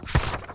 x_enchanting_scroll.8.ogg